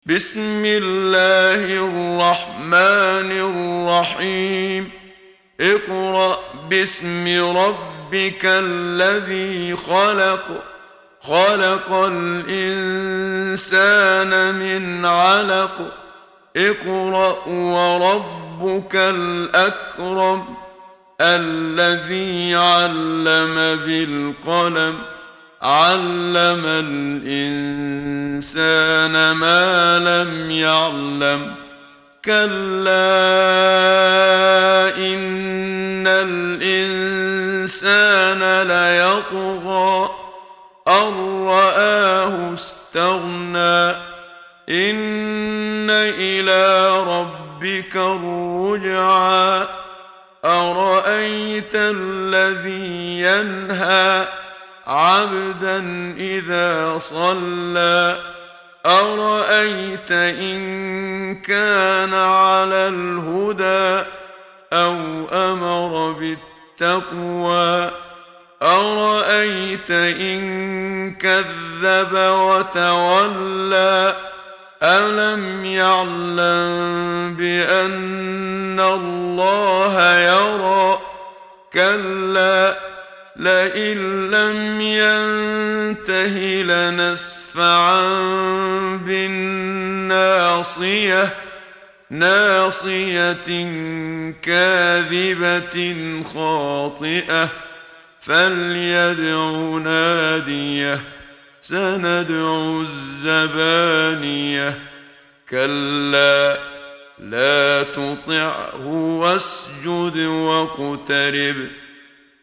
محمد صديق المنشاوي – ترتيل – الصفحة 8 – دعاة خير